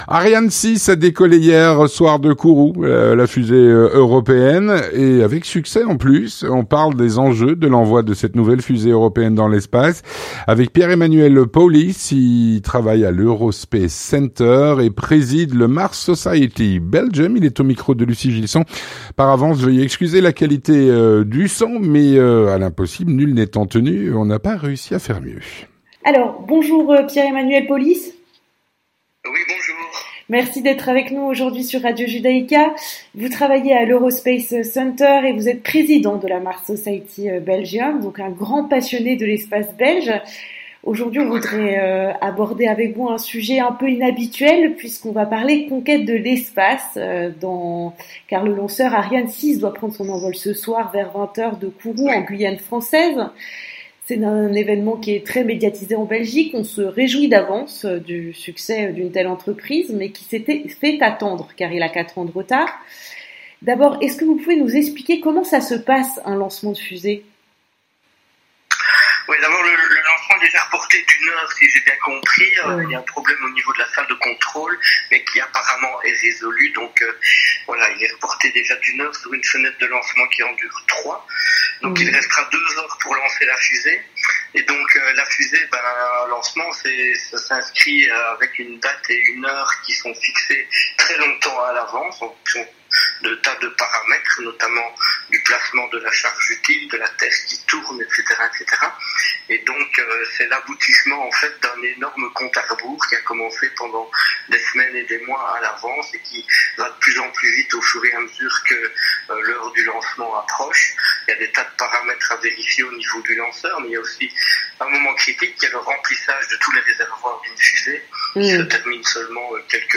L'entretien du 18H - Ariane 6, la fusée européenne, a décollé hier soir de Kourou.